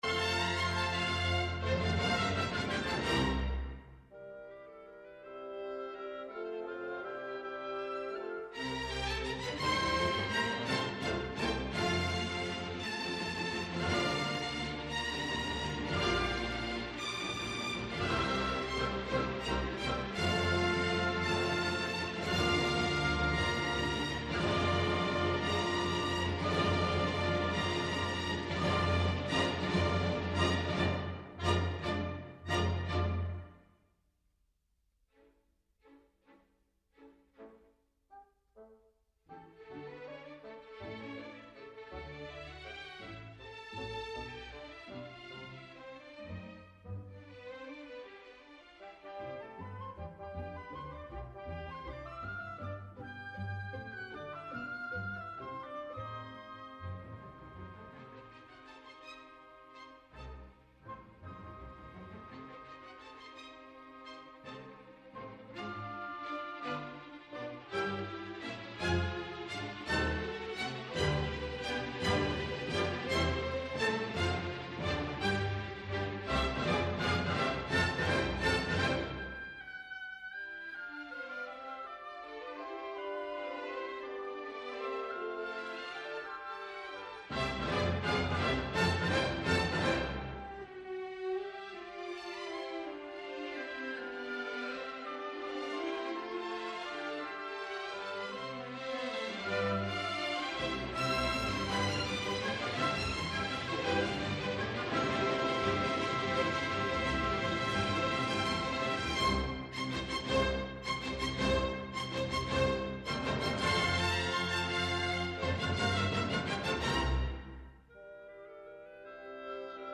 Η Σωστή Ώρα στο Πρώτο Πρόγραμμα της Ελληνικής Ραδιοφωνίας